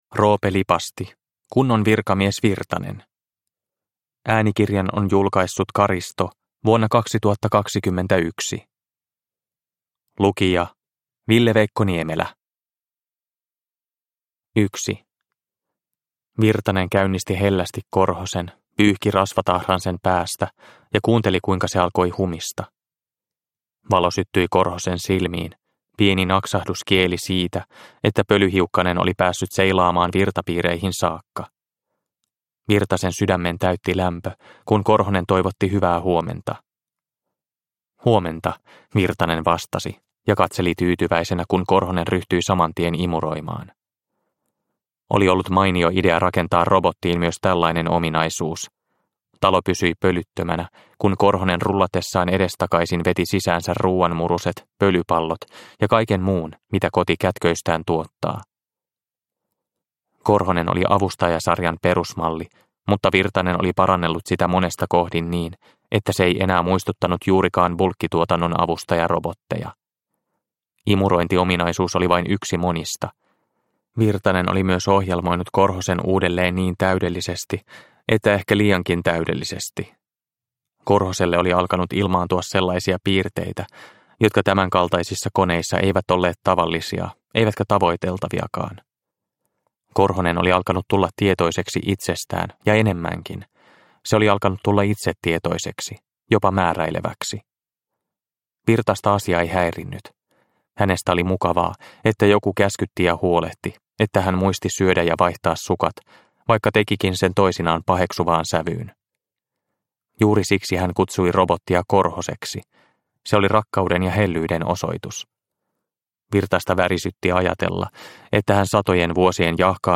Kunnon virkamies Virtanen – Ljudbok